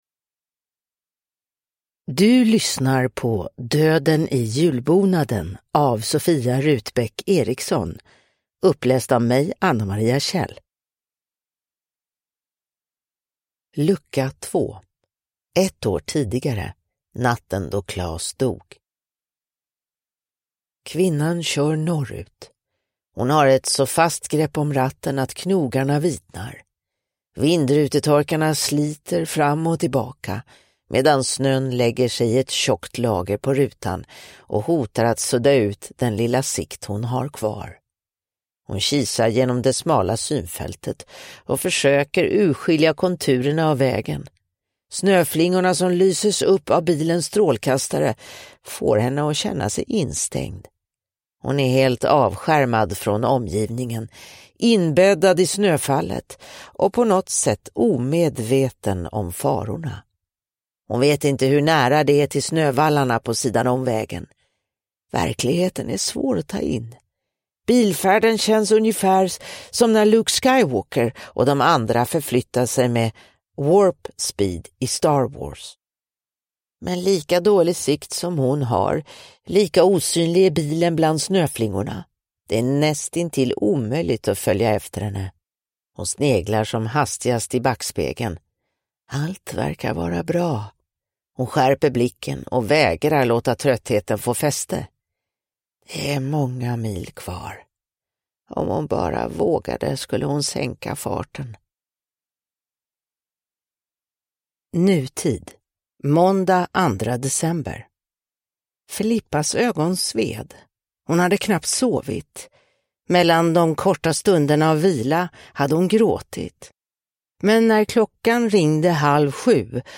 Döden i julbonaden: Lucka 2 – Ljudbok